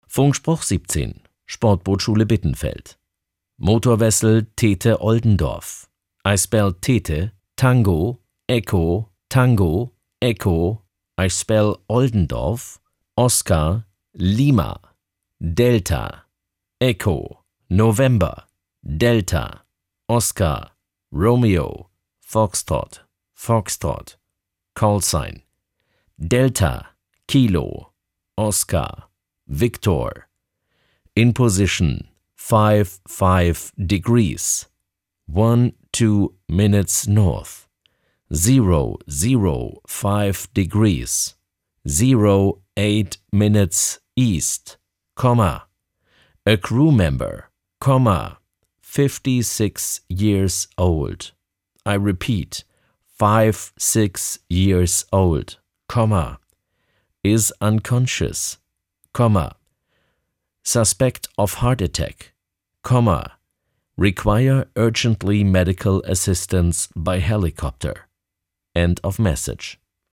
Funkspruch 17 – Sportbootschule Bittenfeld
Funkspruch-17.mp3